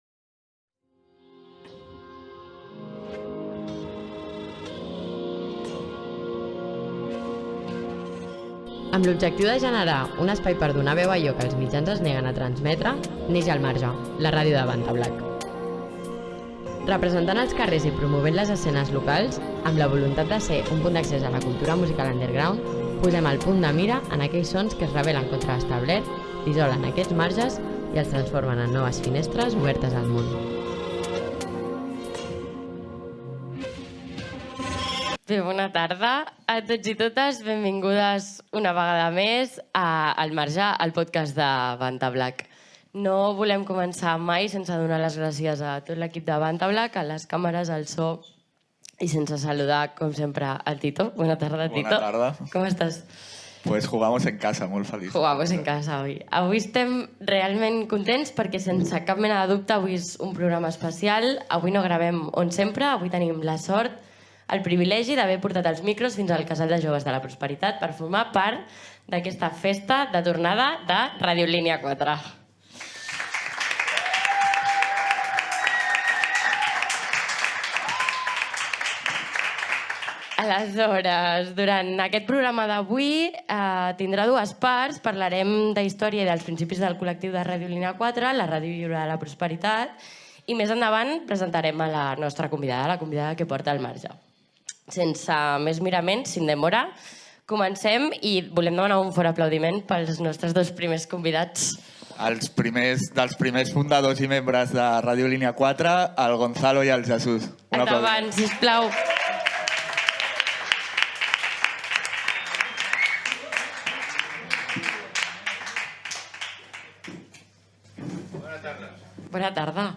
Presentació i entrevista a dos dels integrants de Radio Línea IV que expliquen com van ser els inicis d'aquella ràdio lliure i de Tele Prospe